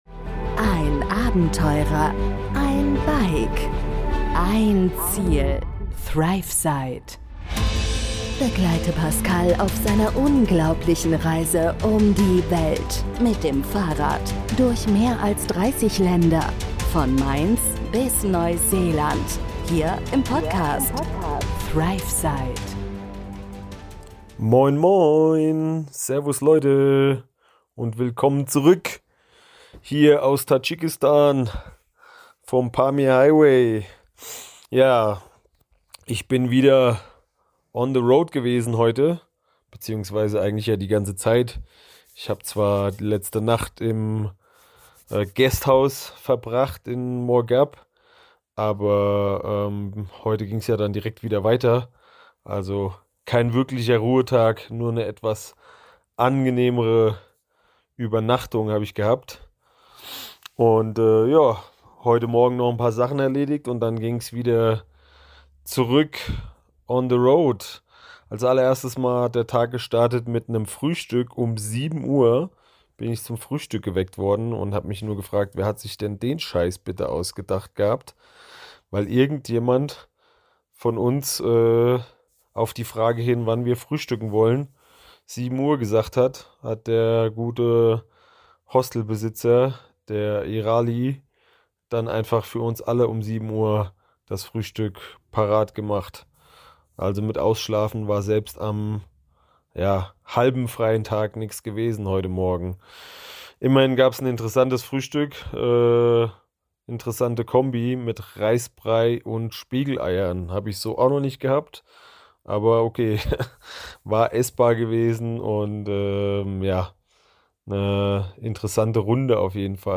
Ich melde mich direkt vom Pamir Highway in Tadschikistan und berichte euch von einem weiteren abgefahrenen Tag auf meiner Reise!